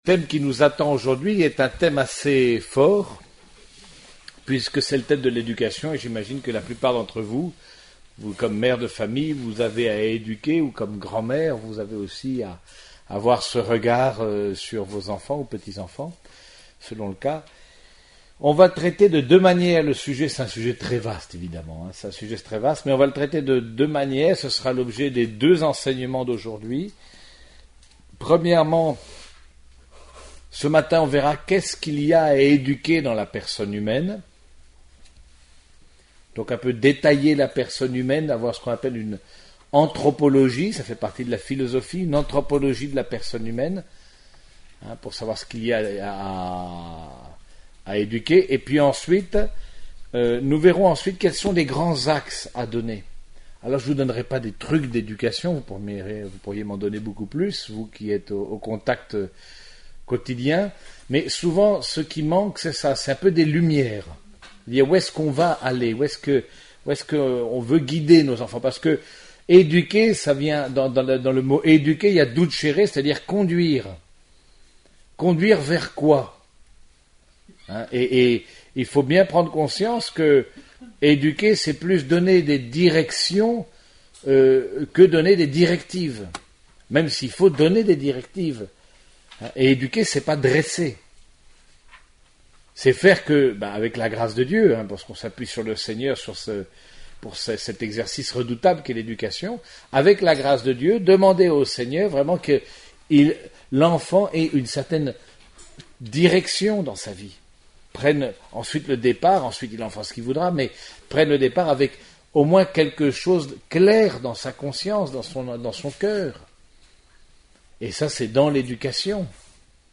Écoutez la conférence audio en deux parties :